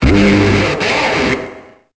Cri de Blizzaroi dans Pokémon Épée et Bouclier.